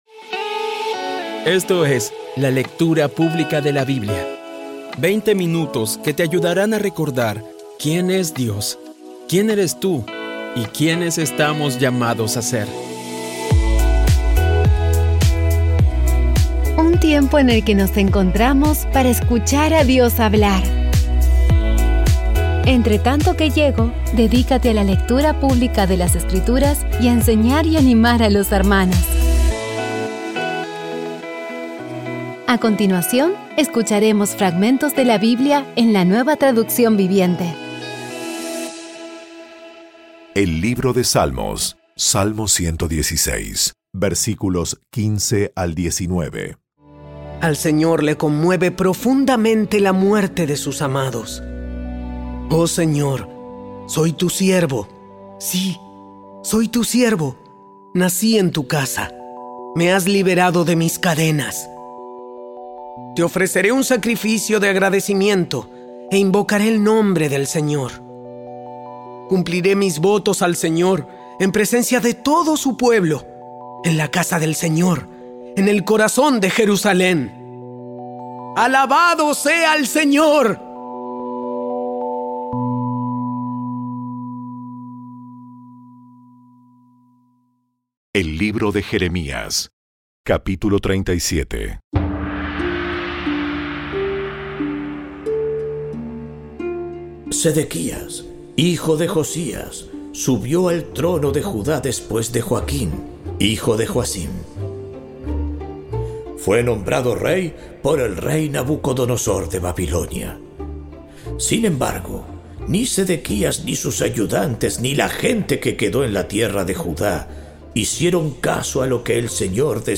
Audio Biblia Dramatizada Episodio 290
Poco a poco y con las maravillosas voces actuadas de los protagonistas vas degustando las palabras de esa guía que Dios nos dio.